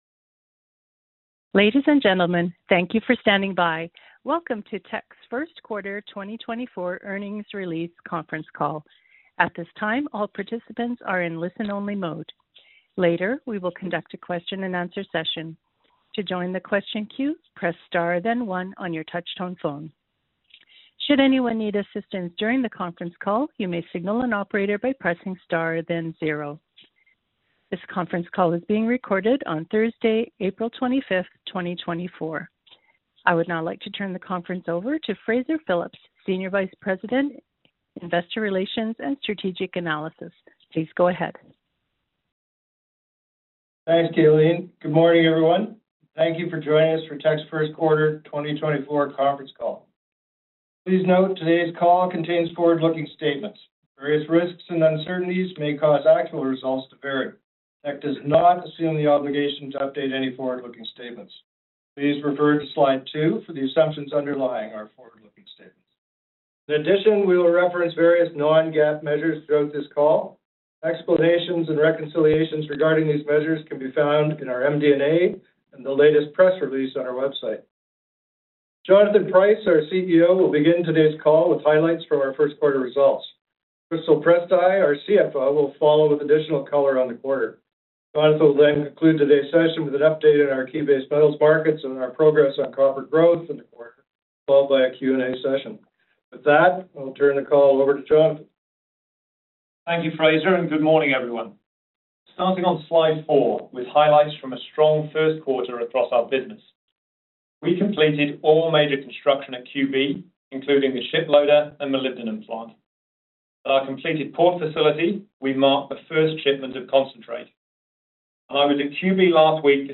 Q1-2024-Financial-Report-Conference-Call-Audio.mp3